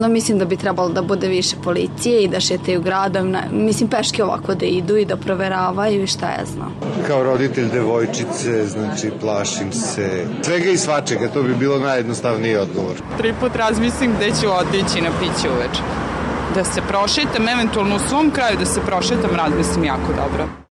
Građani Novog Sada o bezbednosti